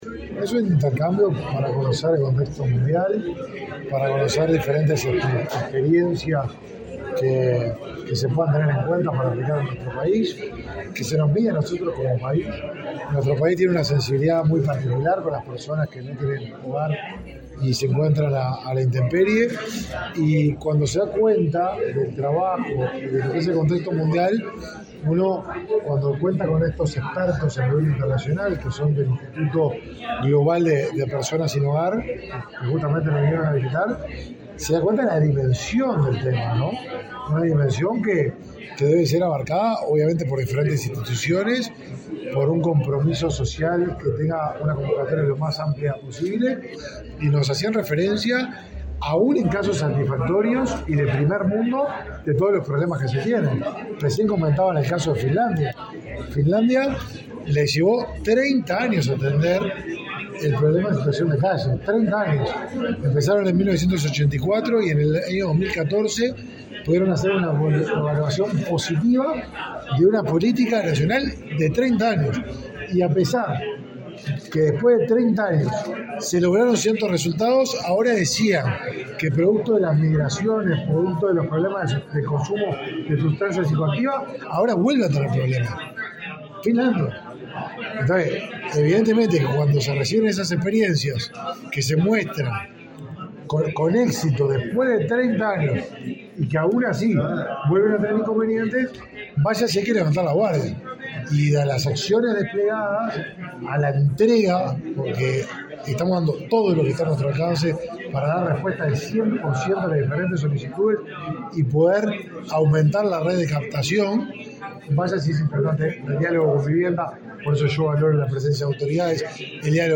Declaraciones a la prensa del titular del Mides, Martín Lema
Declaraciones a la prensa del titular del Mides, Martín Lema 28/07/2023 Compartir Facebook X Copiar enlace WhatsApp LinkedIn Tras participar en una instancia de intercambio con referentes del Instituto Global de Personas Sin Hogar (IGH, por su sigla en inglés), este 28 de julio, el titular del Ministerio de Desarrollo Social (Mides), Martín Lema, realizó declaraciones a la prensa.